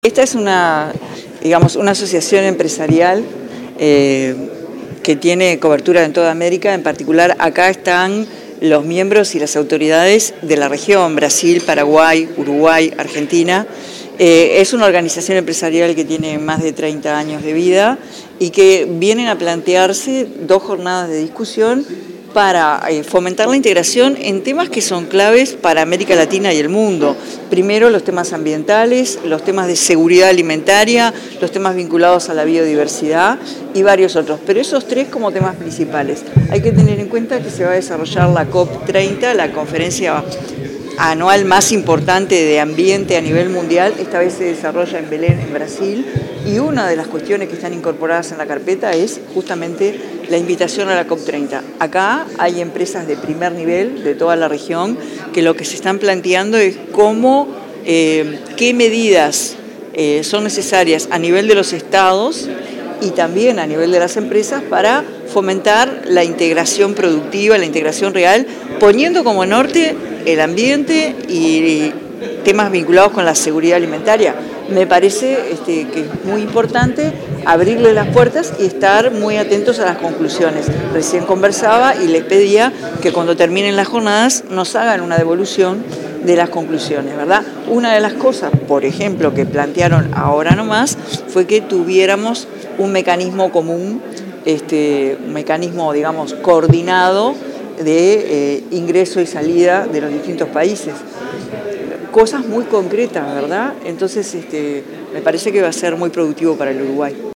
Declaraciones de la presidenta en ejercicio, Carolina Cosse
Luego, dialogó con los medios de comunicación.